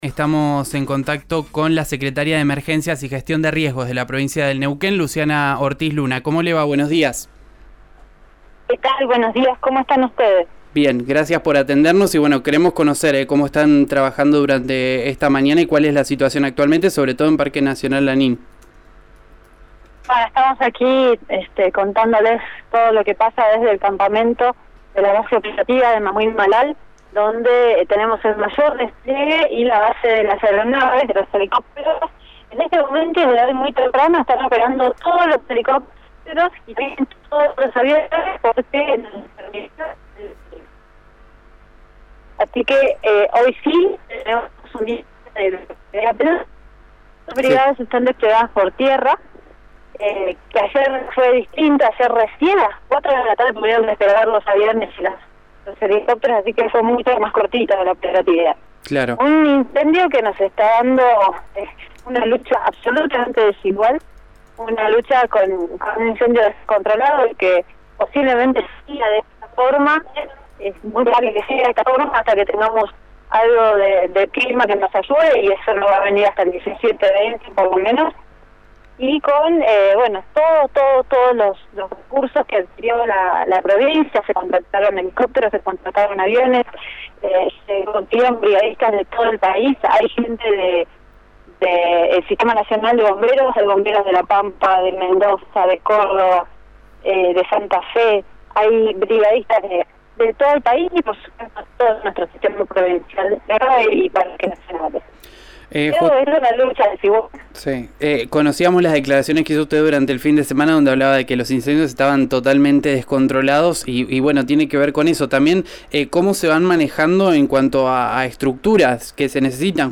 «Este es un incendio que nos está dando una lucha absolutamente desigual, una lucha con un incendio descontrolado que posiblemente siga de esta forma», dijo la funcionaria en comunicación con RÍO NEGRO RADIO.
Escuchá a Luciana Ortiz Luna en RÍO NEGRO RADIO: